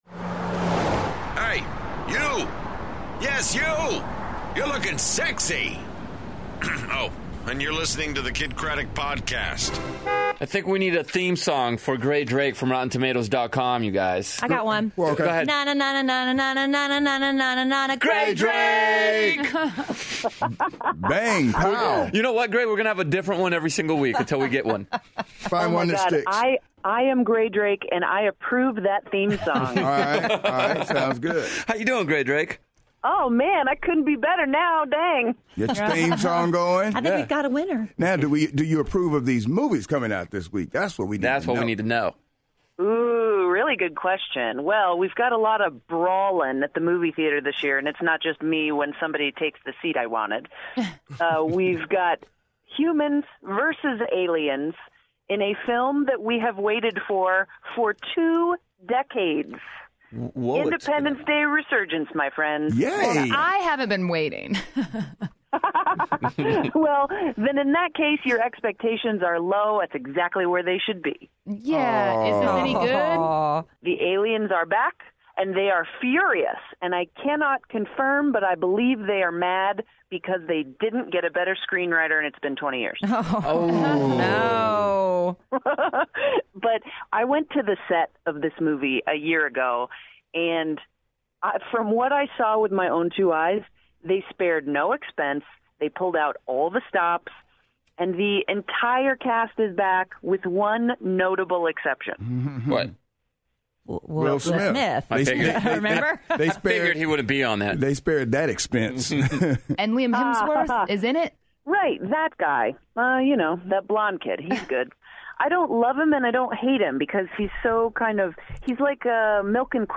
Sofia Carson Performs In The CanalSide Lounge